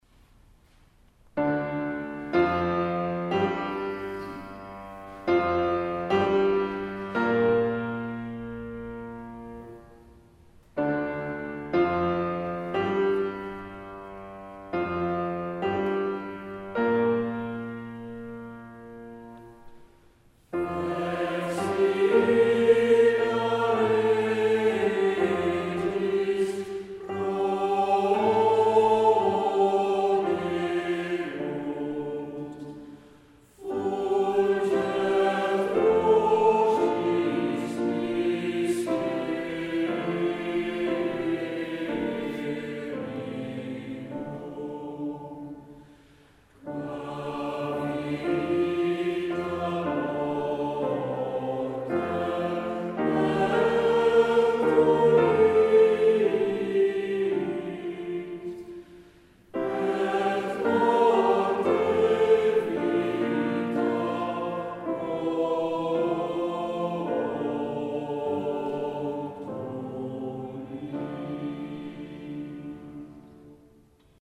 Op deze pagina zijn diverse live opnamen te horen,
...geen studio kwaliteit...
Franz Liszt Kamerkoor - Audio
live opname concert  23 maart 2011, de Via Crucis - Franz Liszt: